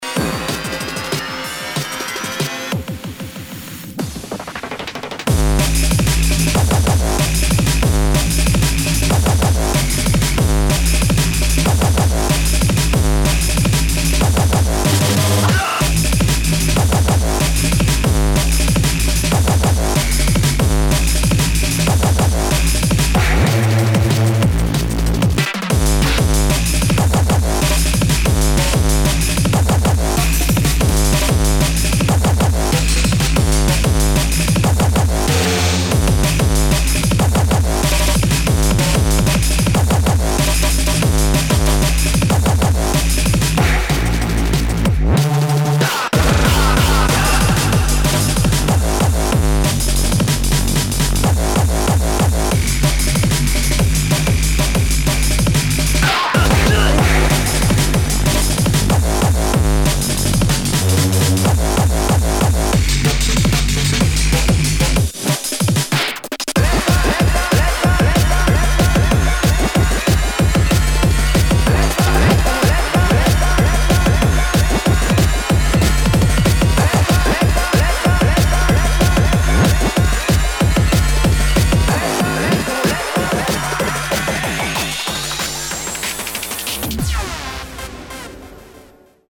[ HARDCORE | BREAKCORE | JUNGLE ]